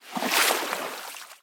water-09.ogg